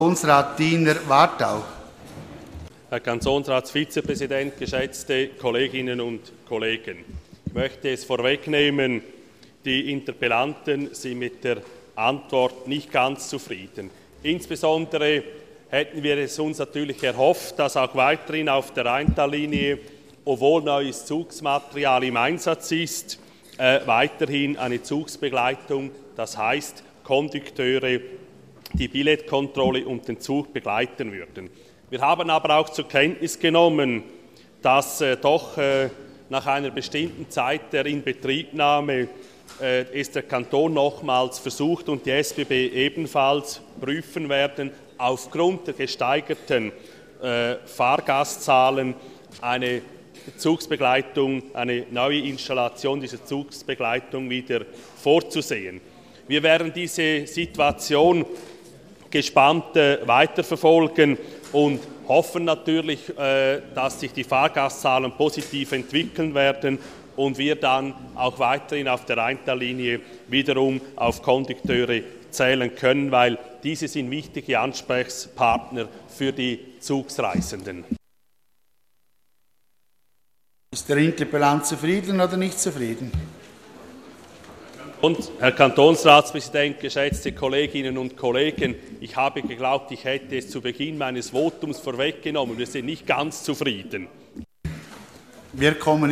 16.9.2013Wortmeldung
Session des Kantonsrates vom 16. bis 18. September 2013